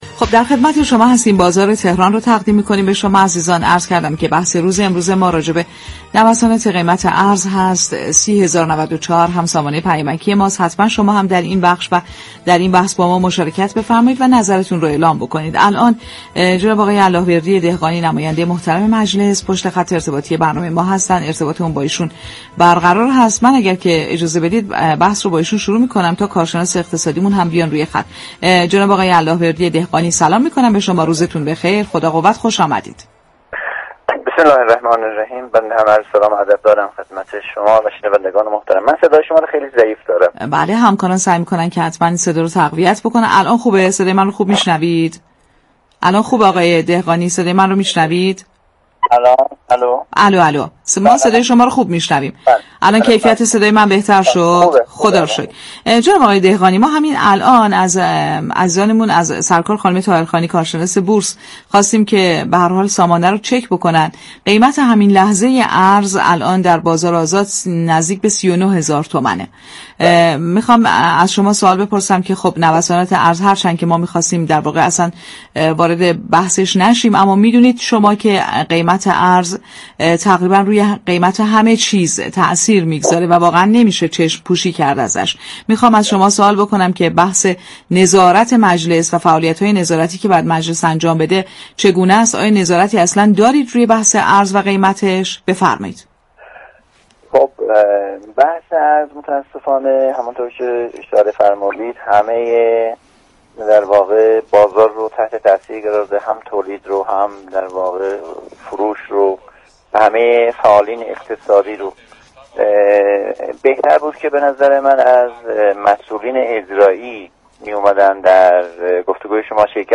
به گزارش پایگاه اطلاع رسانی رادیو تهران، الله‌وردی دهقانی عضو كمیسیون صنایع و معادن مجلس شورای اسلامی در گفت و گو با " بازار تهران " رادیو تهران درخصوص افزایش نرخ ارز گفت: مجلس در هفته های گذشته این موضوع را مورد بررسی قرار داده است و در این خصوص پرسش هایی از رئیس جمهور، بانك مركزی و وزیر اقتصاد داشته و دارد و قطعا محور اصلی جلسات مجلس در هفته پیش رو گرانی و نوسات بازار ارز خواهد بود.